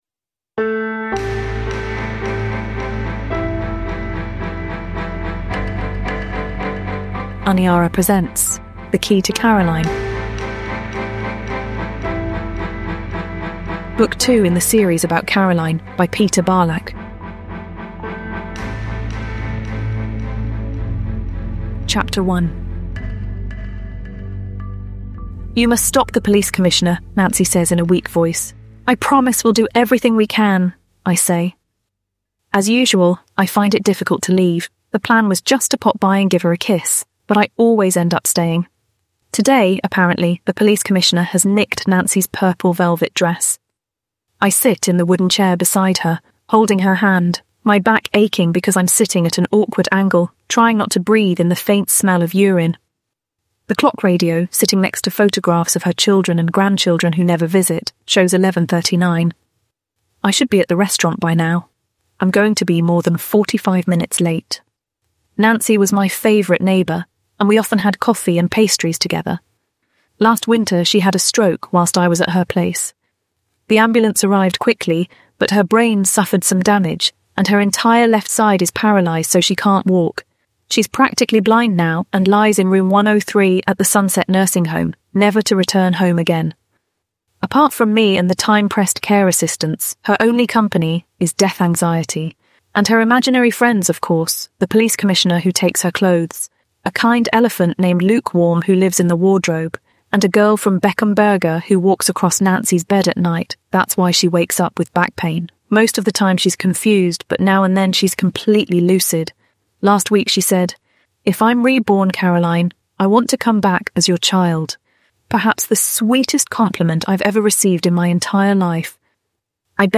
The Key to Caroline – Ljudbok
Uppläsare: Tora (AI Voice)